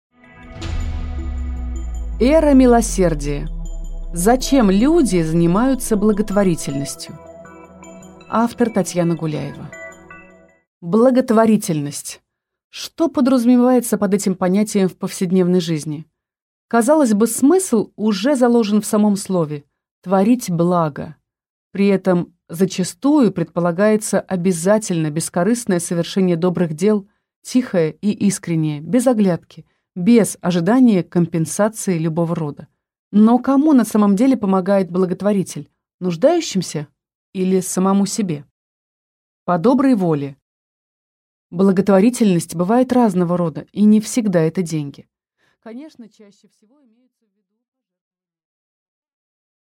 Аудиокнига Эра милосердия | Библиотека аудиокниг